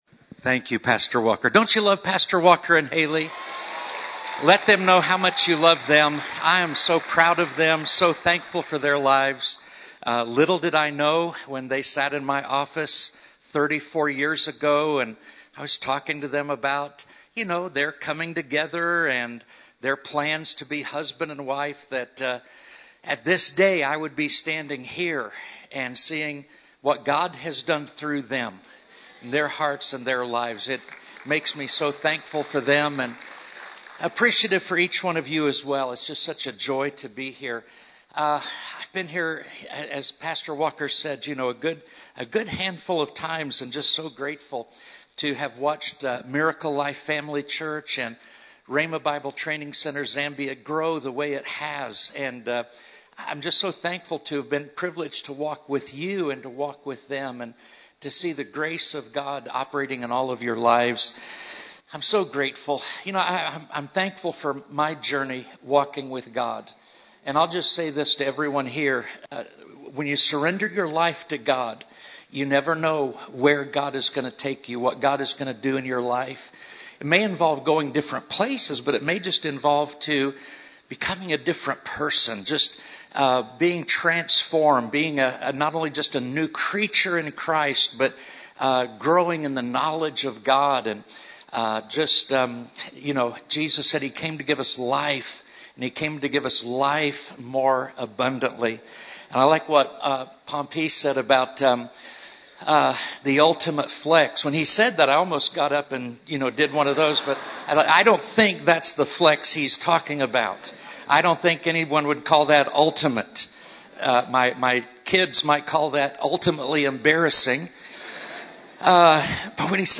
A message from the series "Individual Sermons."